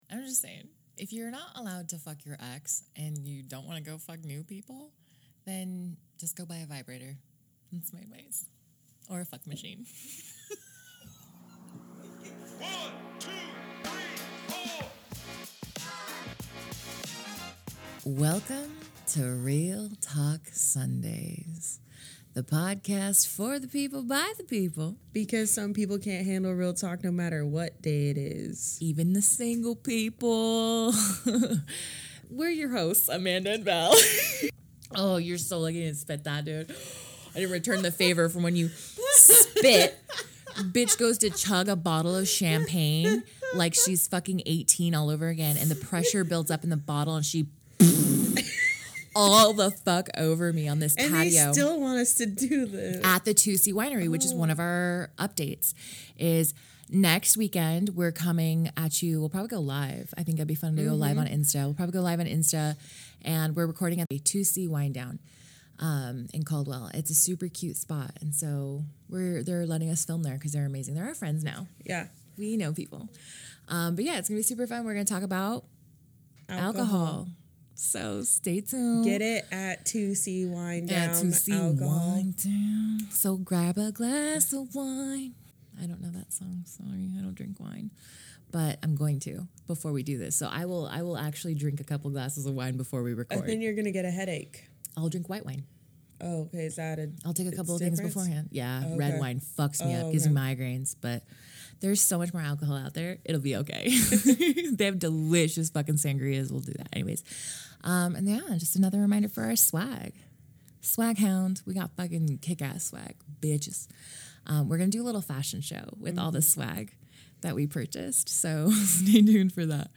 Prepare for a whirlwind of stages that both men and women encounter when relationships shatter. Enjoy stories and literal side-splitting laughter as these witty gurus decode the cryptic world of ending relationships.